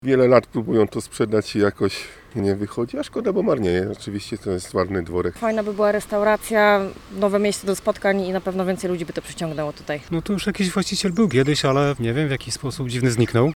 Co o dworku mówią mieszkańcy Osiedla Bajkowego?